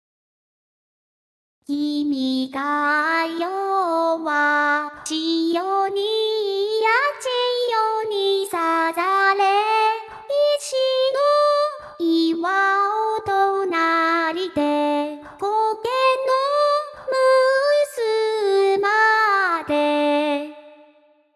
サンプルとして「君が代」（BPM=140と速いっす）で作業してみました。
コーラス（粗隠し＋リアル化）
リバーブ（粗隠し＋リアル化）
ここまでのエフェクトをかけたもの